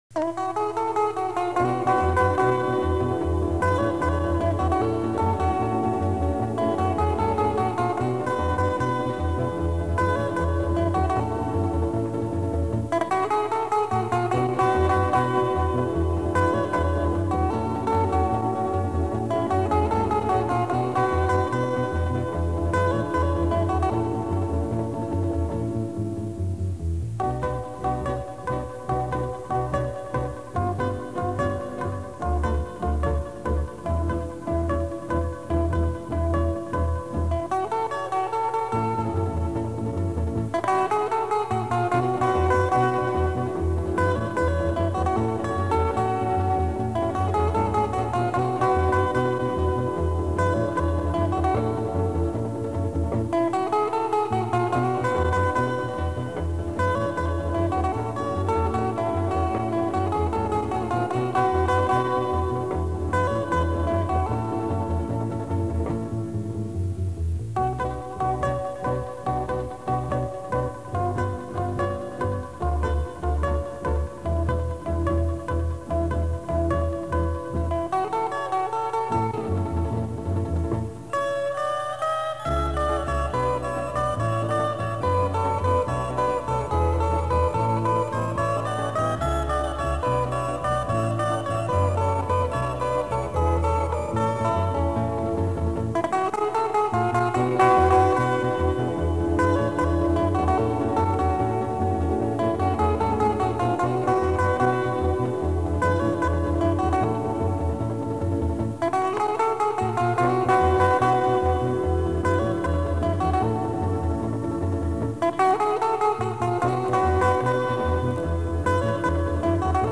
instrumentale låter
I mars 1965 spilte vi inn ni melodier på bånd